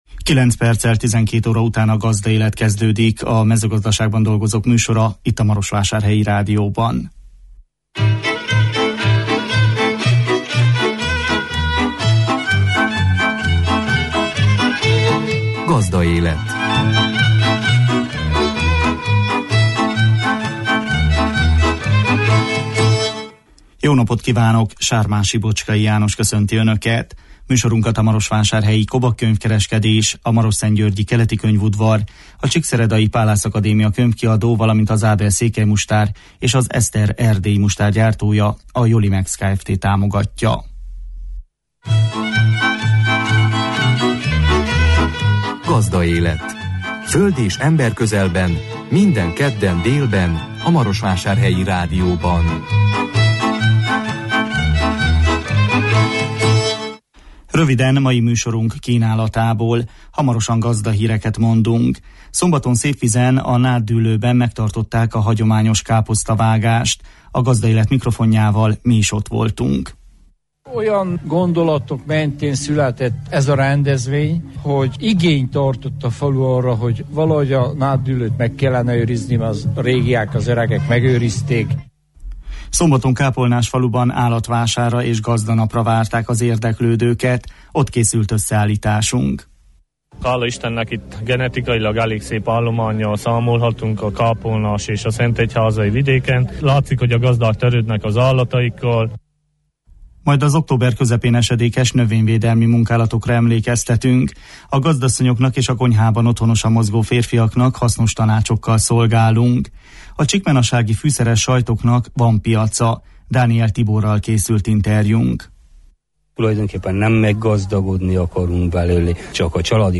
A Gazdaélet mikrofonjával mi is ott voltunk. Szombaton Kápolnásfaluban állatvásárra és gazdanapra várták az érdeklődőket. Ott készült összeállításunk.